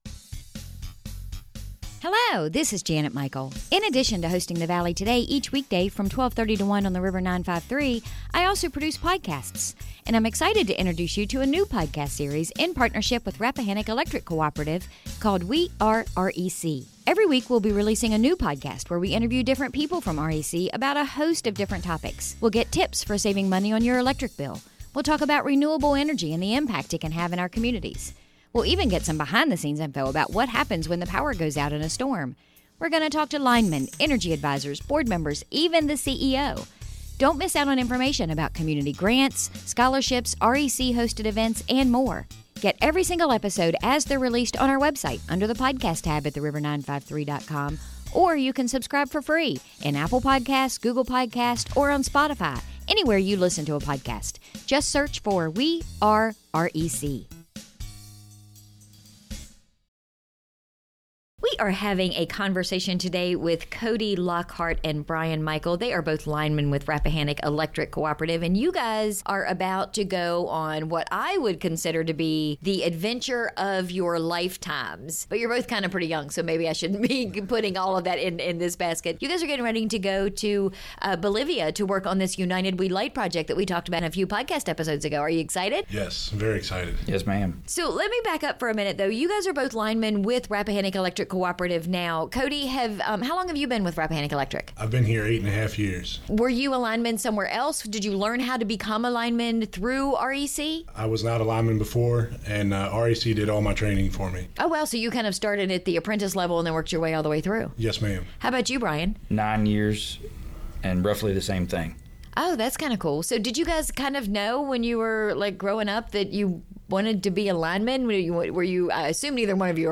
We had a conversation today with two of REC’s linemen who will be traveling to Bolivia as part of the United We Light project.